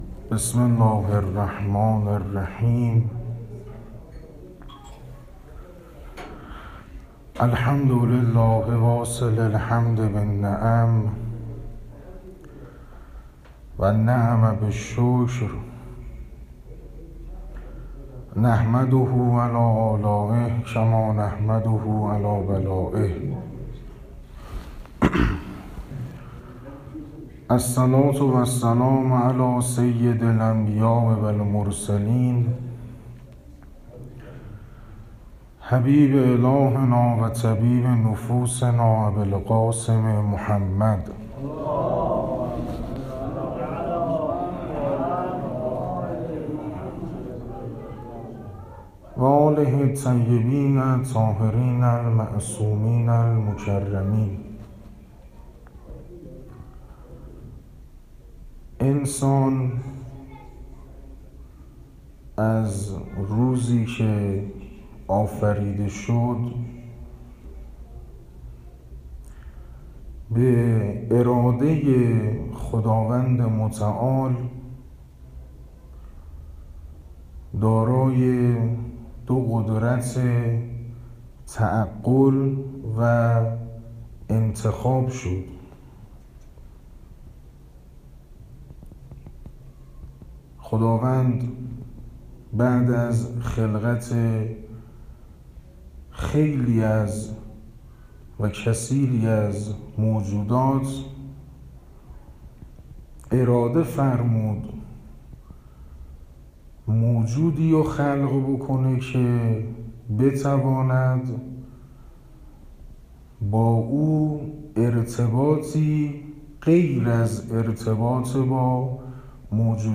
سخنرانی دهه اول محرم مسجد محمد رسول الله صفات شیعه ۳